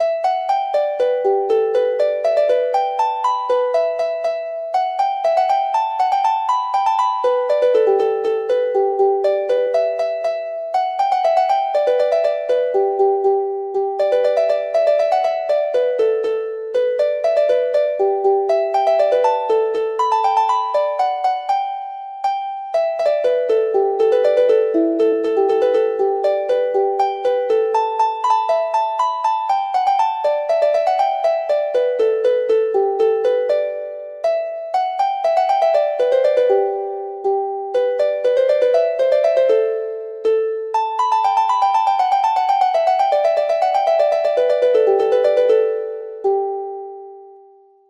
Harp version
Traditional Harp